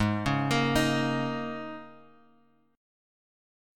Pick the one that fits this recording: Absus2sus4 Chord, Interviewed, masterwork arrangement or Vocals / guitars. Absus2sus4 Chord